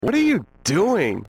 All right so the mystery mod sounds are from a pinball game called Monster Bash.